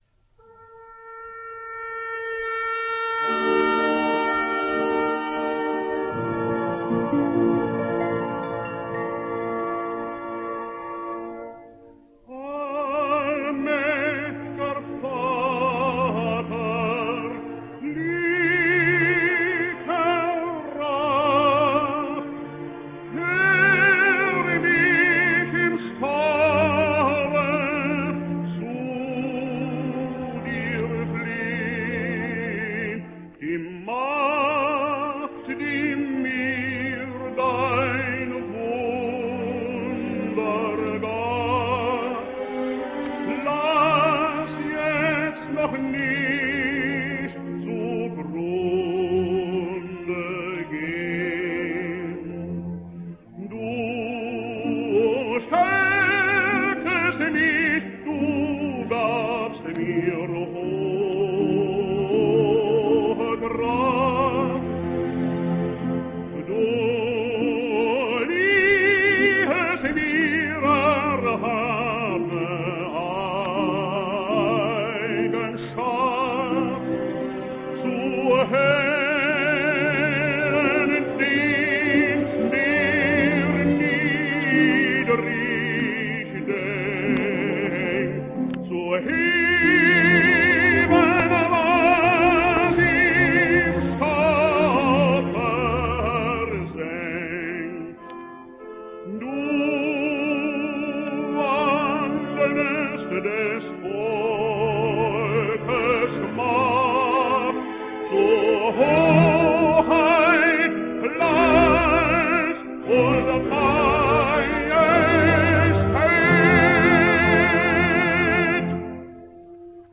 German Tenor.